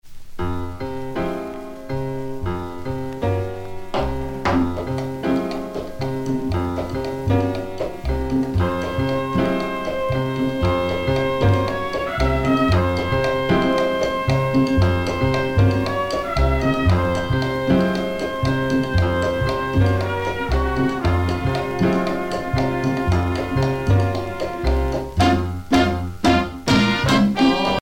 danse : pachanga (Cuba)
Pièce musicale éditée